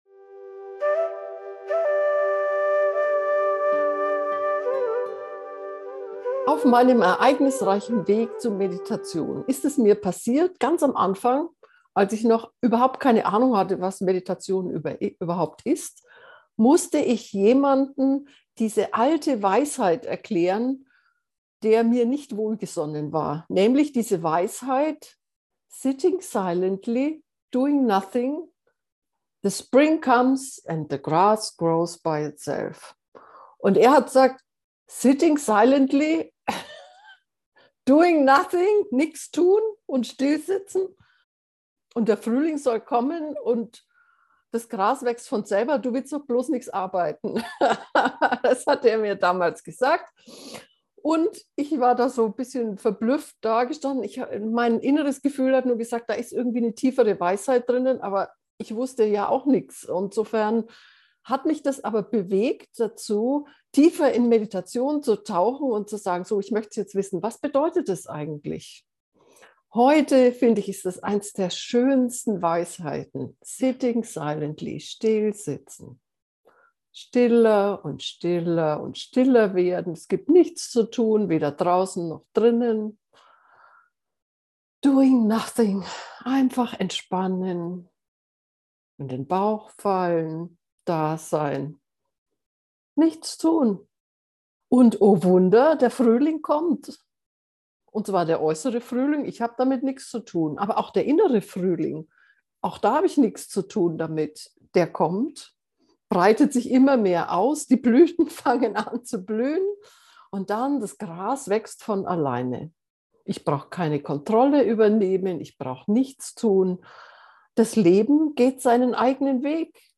still-sitzen-nichts-tun-gefuehrte-meditation